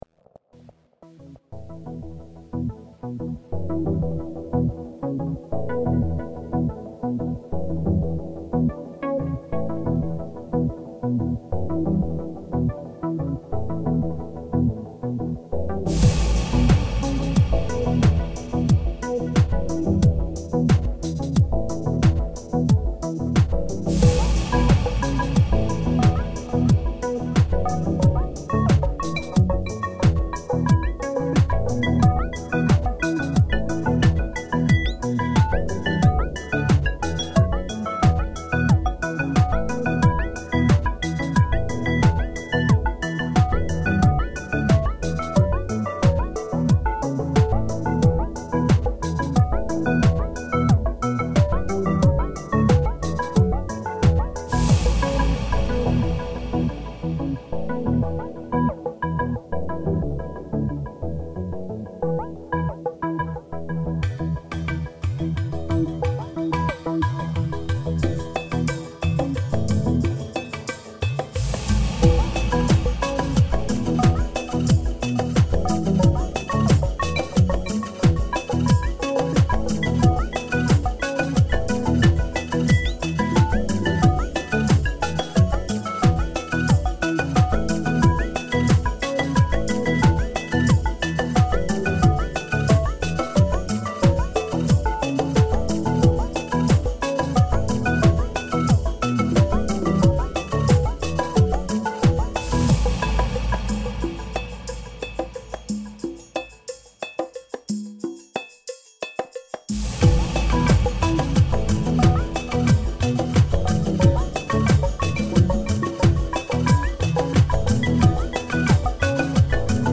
Trotzdem hat es mich gereizt, ob ich sowas auch auf die Reihe – besser gesagt – in den Computer bringe.
Eine nicht wiederzuerkennende Version von Simon & Garfunkel’s Scarborough fair – auf Techno Basis – aber nicht gelogen: Die Melodie dahinter in der Notation entspricht zu weiten Teilen dem Original …
tecno-scarborough-fair.mp3